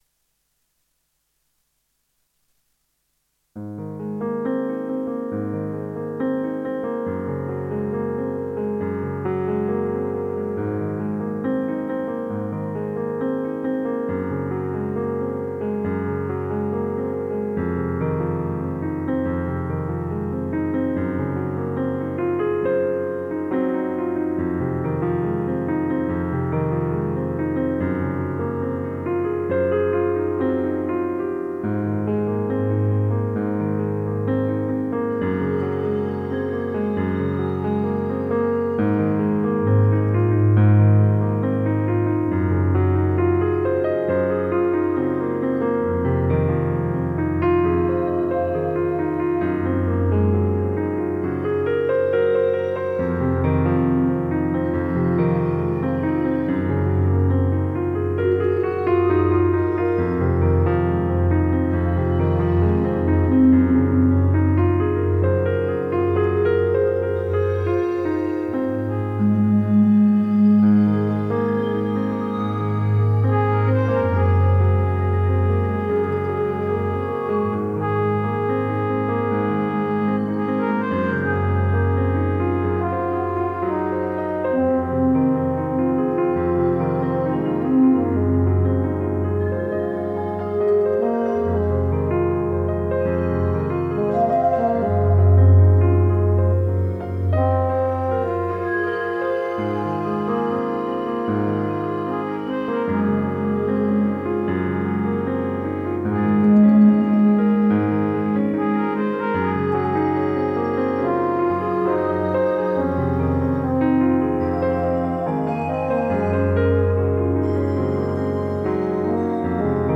Ambience Piano, Brass & Pad – Relax Calm & Peaceful